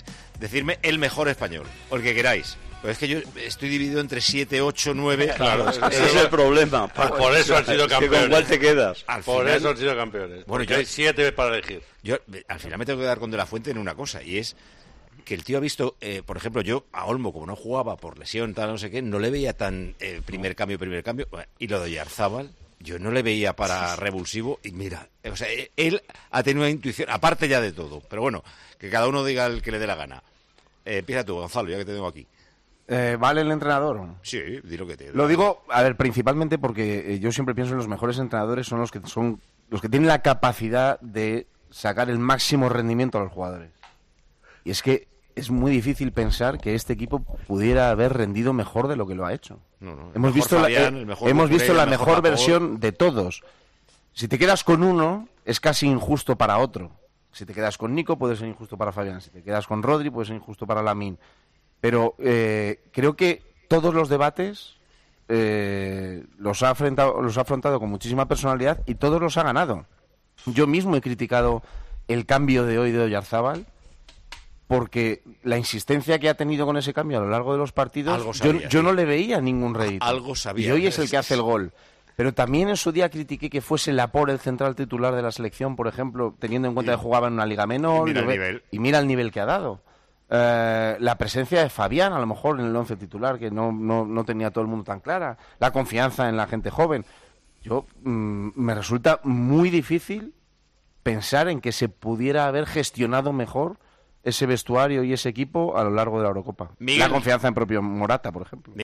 El director de El Partidazo de COPE analizó el triunfo de la selección española que supo reponerse a momentos muy complicados antes de disputar el torneo de Alemania.